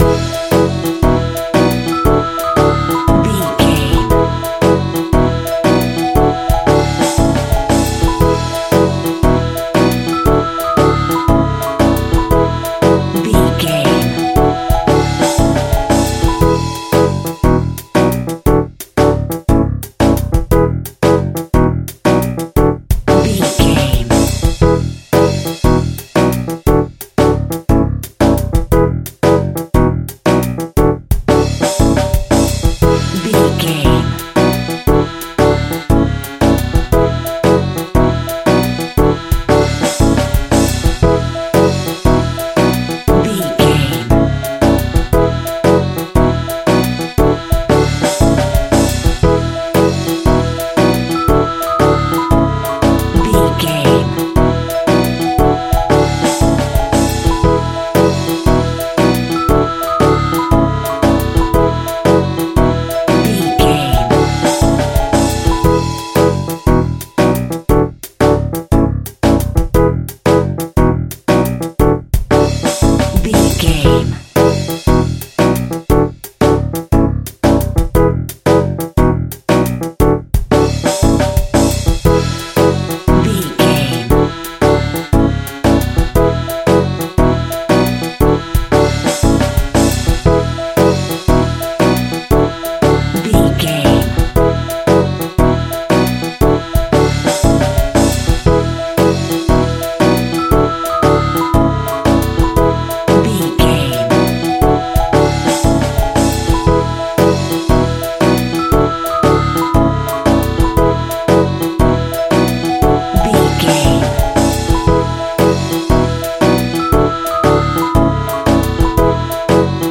Childrens Pop.
Ionian/Major
instrumental music
electronic
drum machine
synths
strings
orchestral
brass